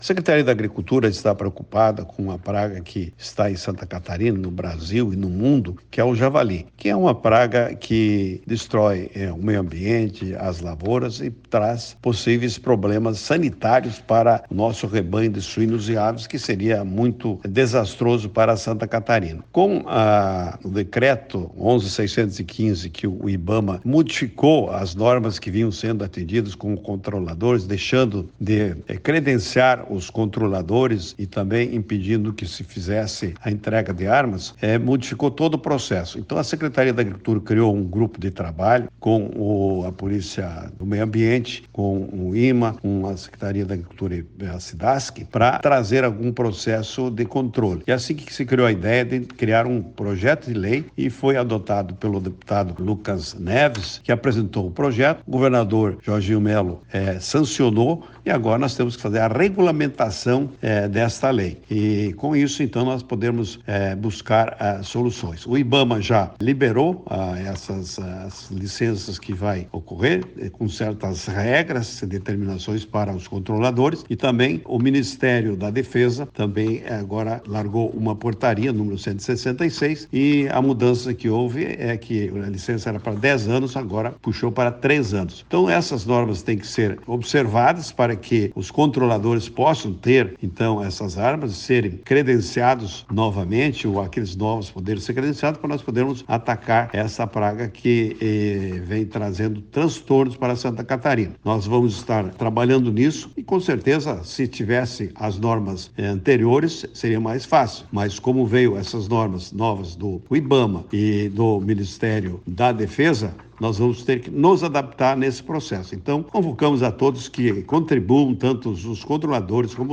O secretário de Estado da Agricultura e Pecuária, Valdir Colatto, ressalta a importância destas medidas para  integrar as formas de manejo sustentáveis e para evitar maiores impactos ambientais e socioeconômicos: